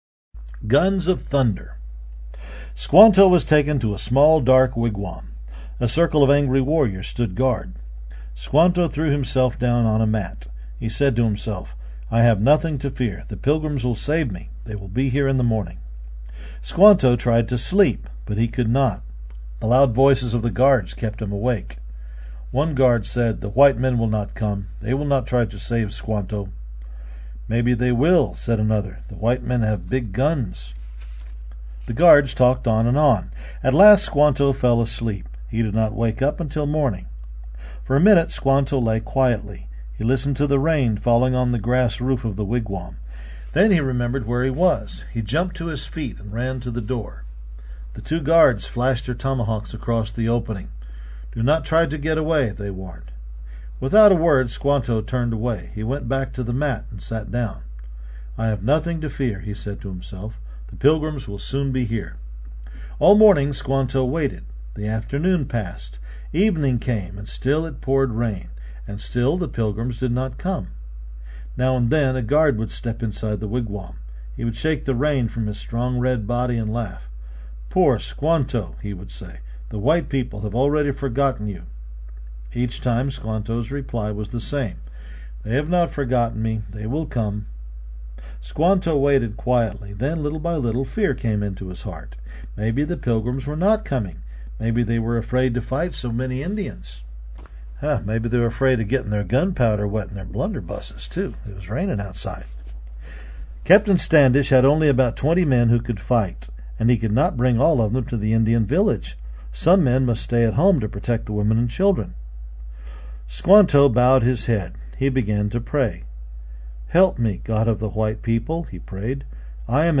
Explore Uncle Rick Audios' library of over 113 engaging audiobooks that teach children history, scripture, and character through captivating storytelling.